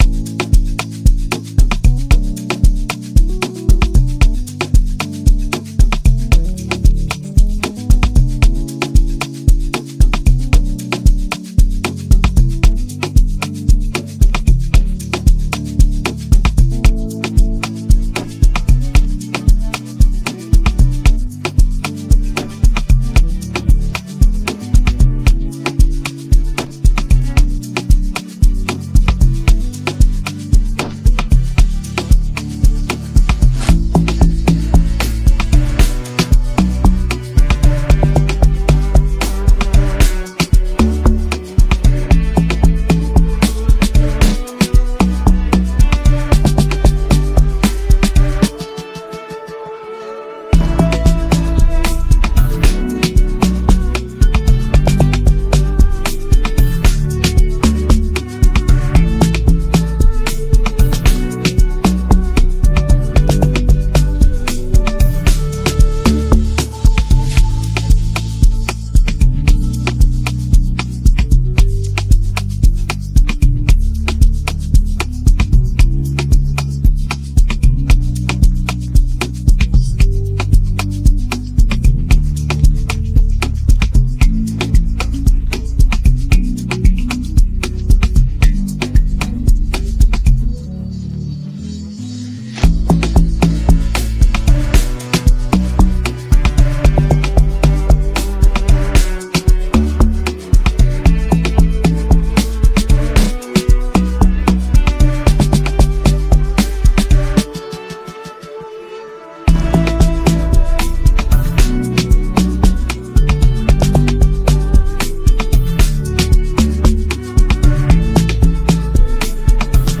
fire free amapiano instrumental